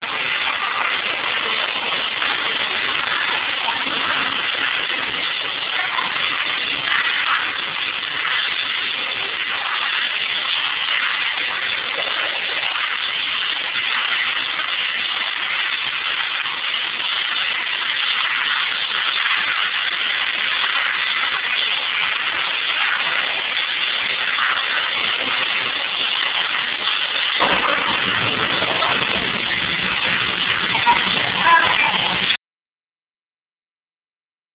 These are a series of short clips from a VHS video shot at the Erie Zoo, Erie Pennsylvania in the late 1980's The videos are in real video format.
African Elephant
elephan1.ram